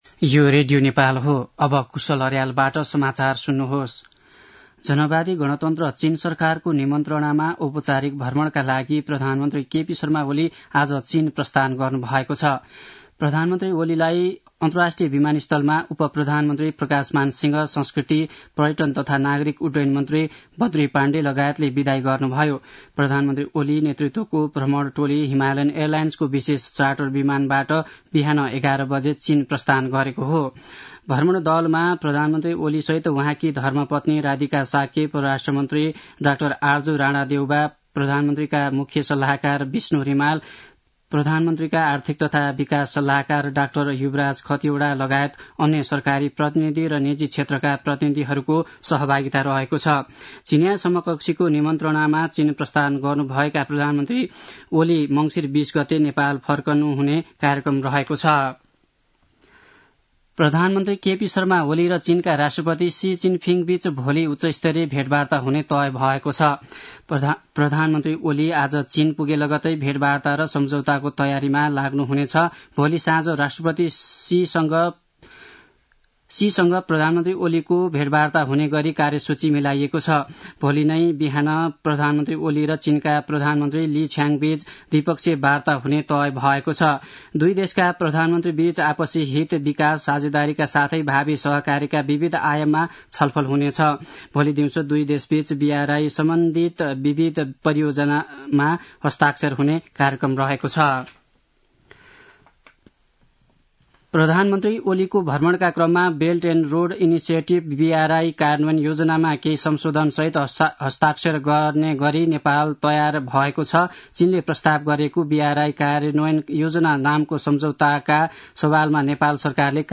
दिउँसो १ बजेको नेपाली समाचार : १८ मंसिर , २०८१
1-pm-nepali-news-1-1.mp3